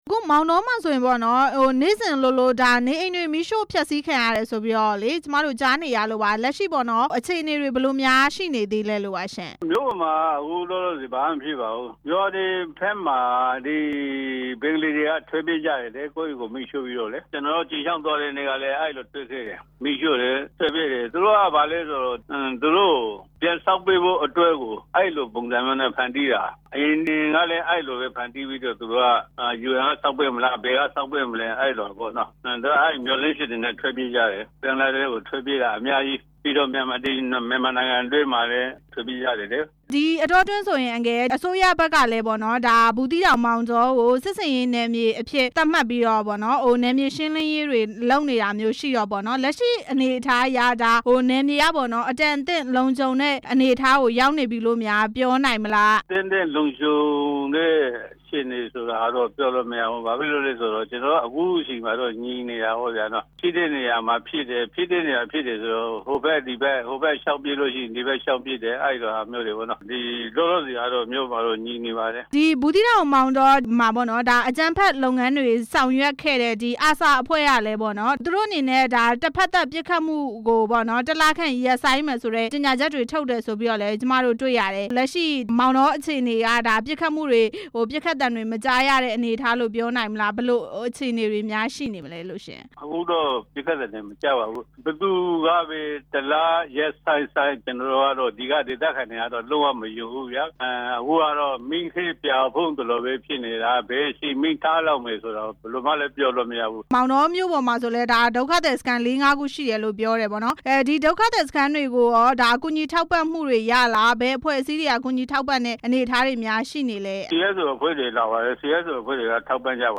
မောင်တောမြို့ အခြေအနေ ဦးမောင်အုန်းကို မေးမြန်းချက်